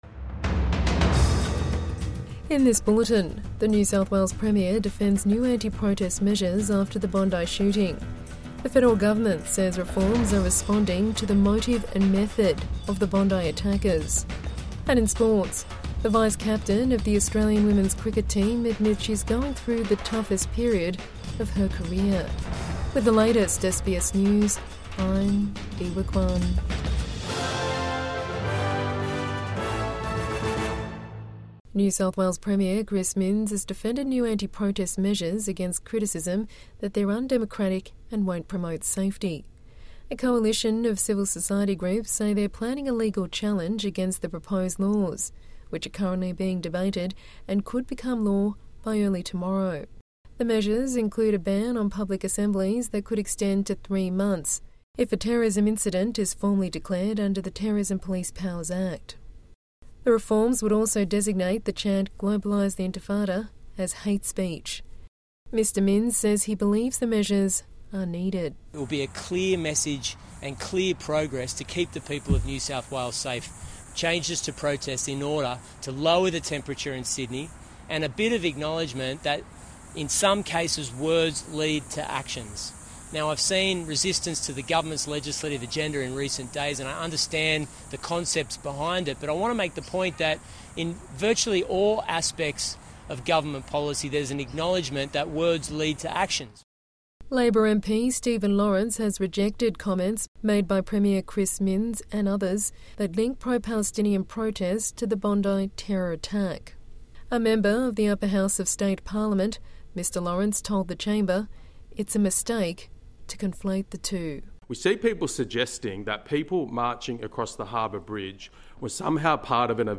NSW Premier defends new anti-protest measures after Bondi shooting | Evening News Bulletin 23 December 2025